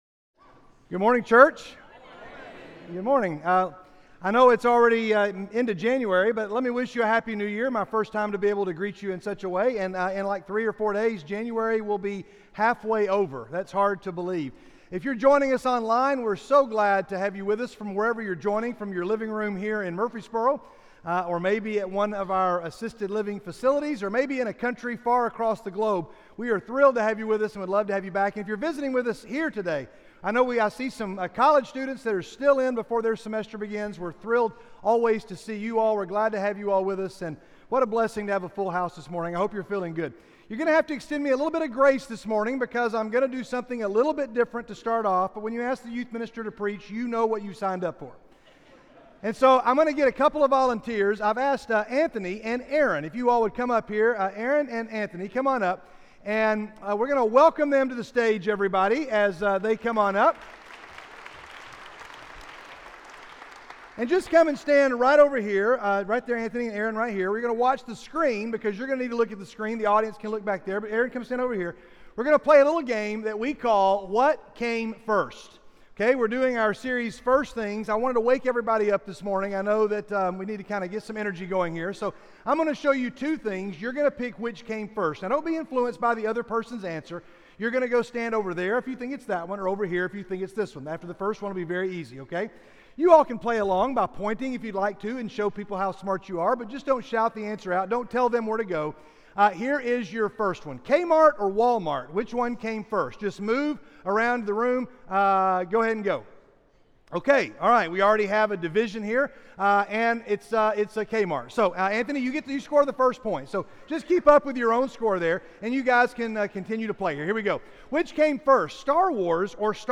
Title 00:00 / 01:04 All Sermons SERMON AUDIO GATHERING Audio download audio download video Download Video Video Also on Who's On First?